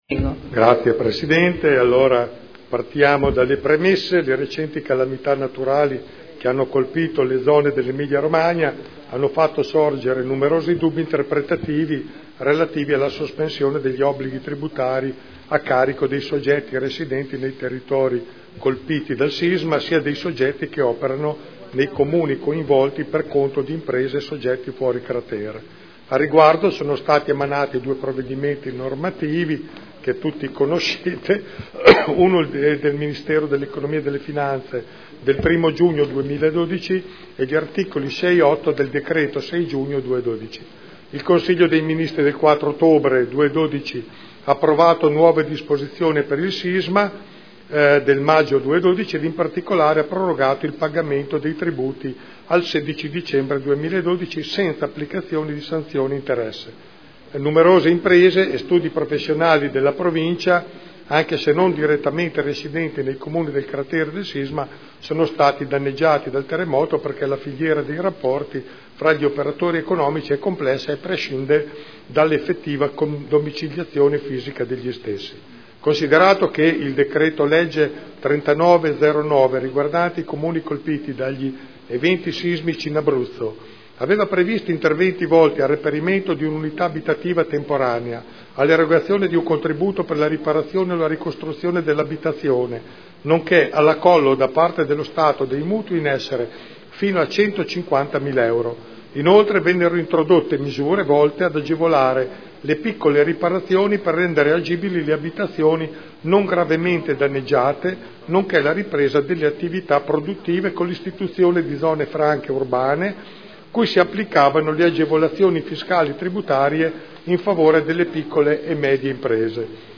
Giancarlo Pellacani — Sito Audio Consiglio Comunale